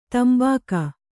♪ tambāka